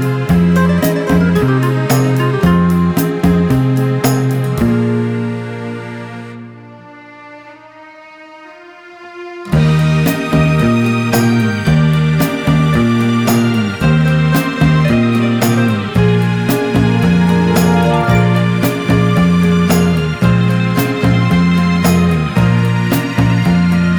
No Backing Vocals Crooners 3:21 Buy £1.50